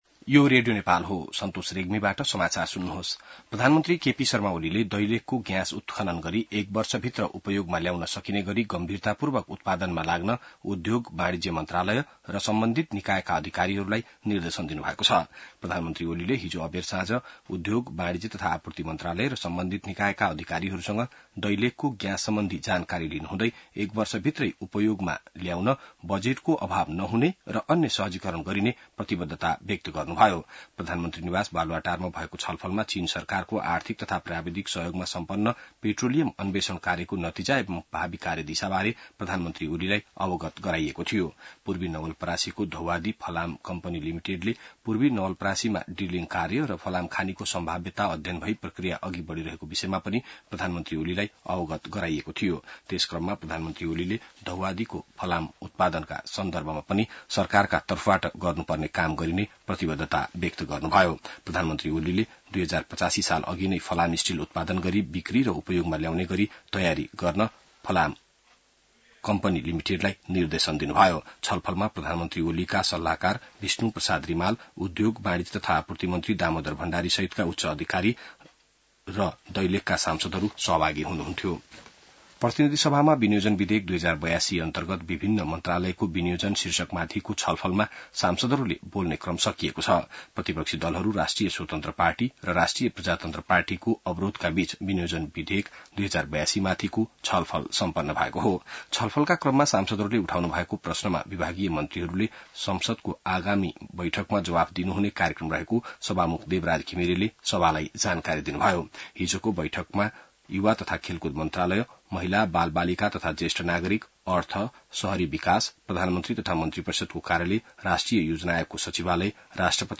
बिहान ६ बजेको नेपाली समाचार : ९ असार , २०८२